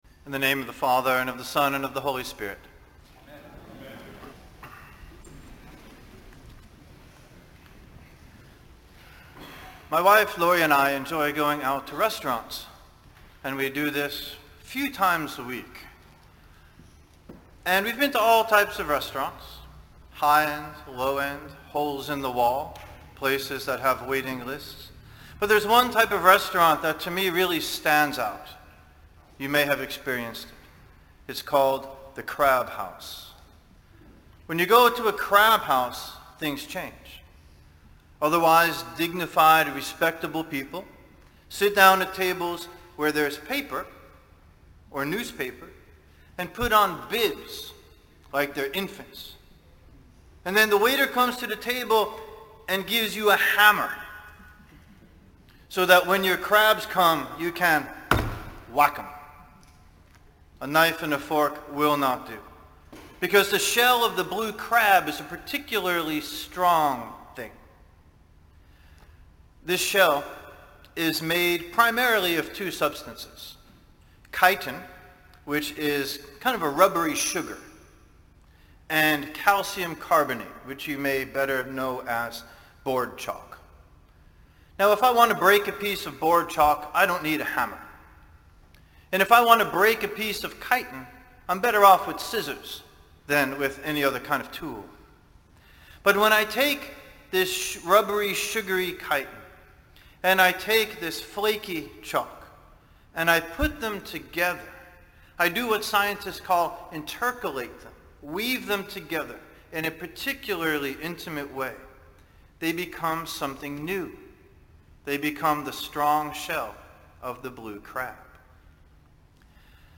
Save Audio In today’s sermon